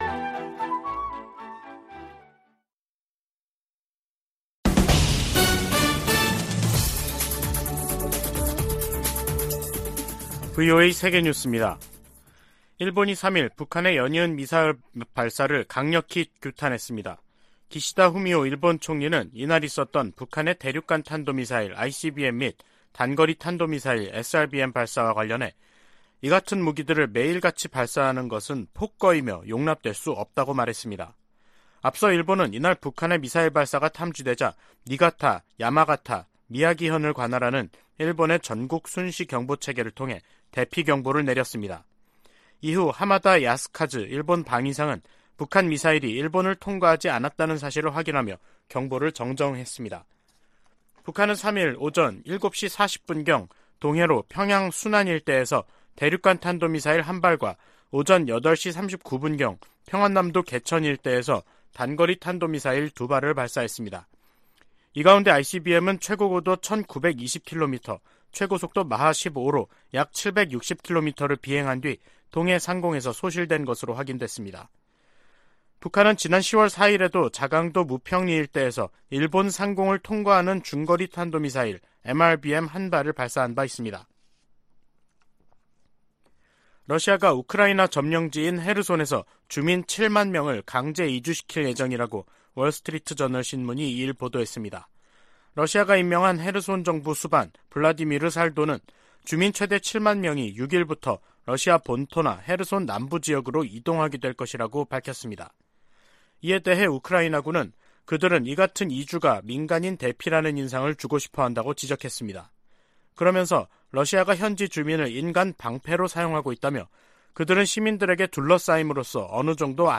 VOA 한국어 간판 뉴스 프로그램 '뉴스 투데이', 2022년 11월 3일 2부 방송입니다. 북한 김정은 정권이 어제 동해와 서해상에 미사일과 포탄을 무더기로 발사한 데 이어 오늘은 대륙간탄도미사일, ICBM을 쏘면서 도발 수위를 높였습니다. ICBM 발사는 실패한 것으로 추정된 가운데 미국과 한국 정부는 확장 억제 실행력을 높이면서 북한의 어떠한 위협과 도발에도 연합방위태세를 더욱 굳건히 할 것을 거듭 확인했습니다.